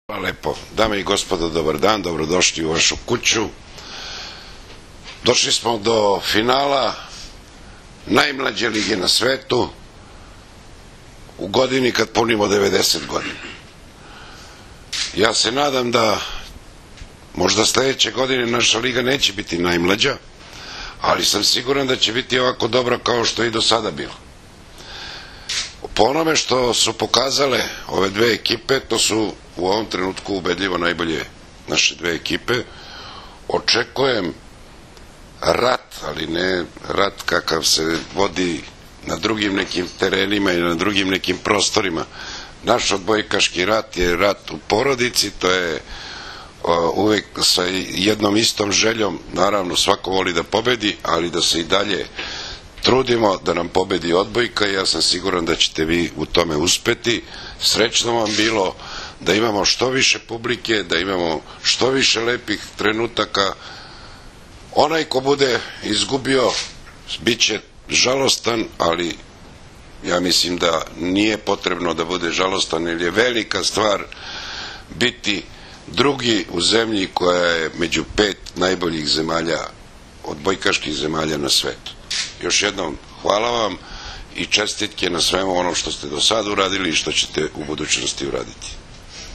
Tim povodom, danas je u prostorijama Odbojkaškog saveza Srbije održana konferencija za novinare